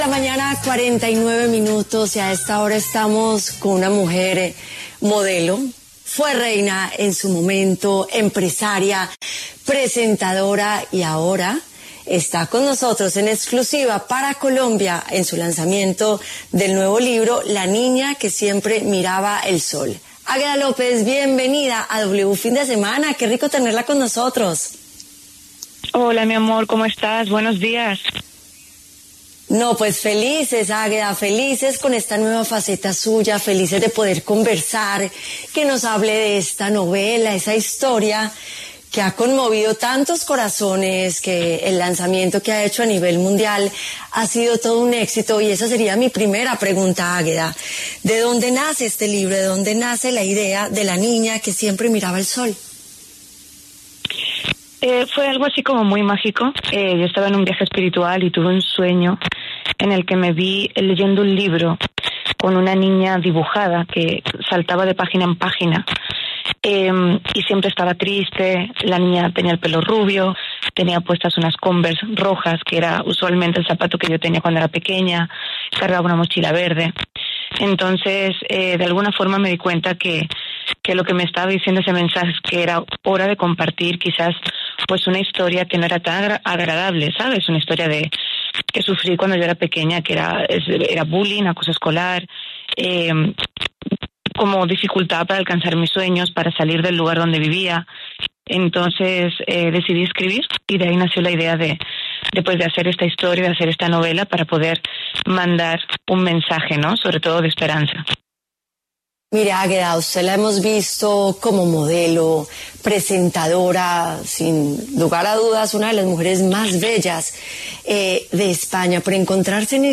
Águeda López, modelo, empresaria y presentadora, contó en W Fin de Semana lo que fue su proceso de sanación y búsqueda de resiliencia en su nueva faceta como escritora con su libro ‘La niña que siempre miraba el sol’.